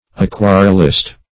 Search Result for " aquarellist" : The Collaborative International Dictionary of English v.0.48: Aquarellist \Aq`ua*rel"list\, n. A painter in thin transparent water colors.
aquarellist.mp3